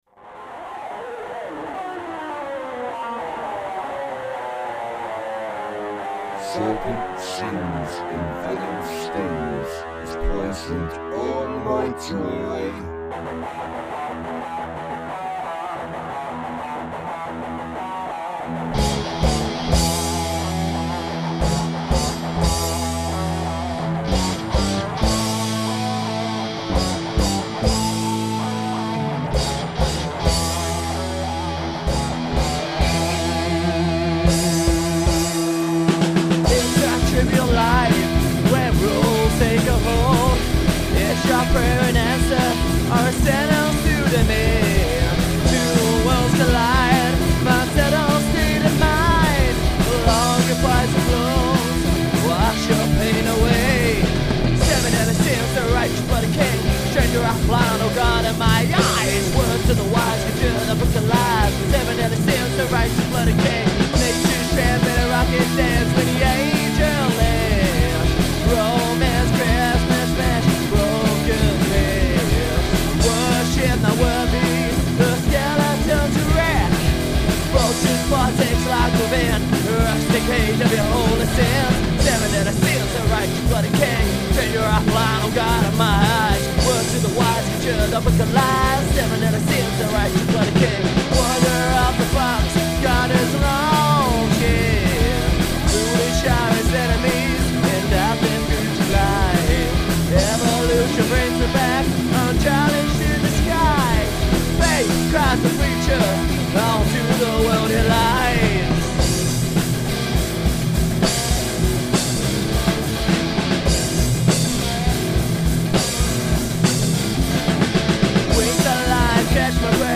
Heavy metal
Thrash/hardcore